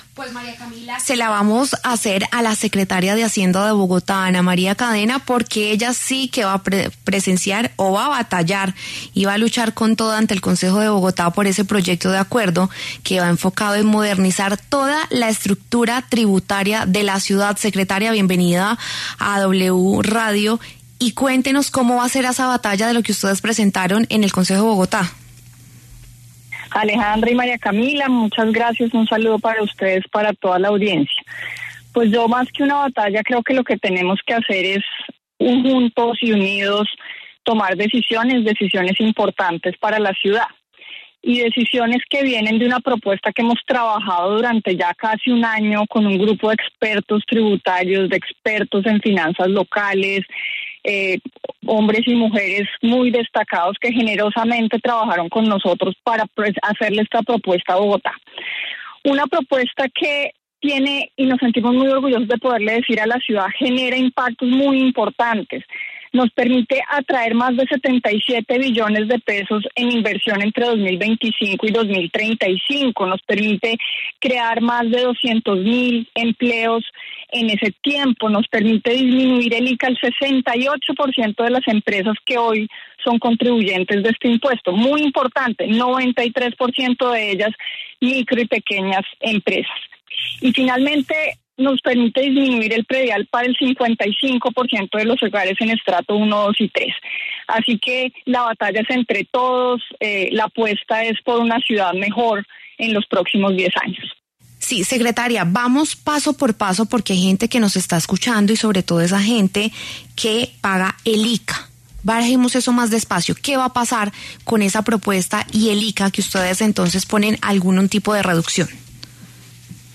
En entrevista con La W, Ana María Cadena, secretaria de Hacienda de Bogotá, entregó detalles del nuevo proyecto de acuerdo que presentó en el Concejo de Bogotá.